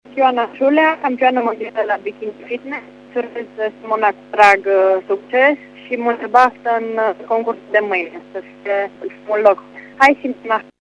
O dovedesc mesajele oamenilor de sport din județele Mureș și Harghita: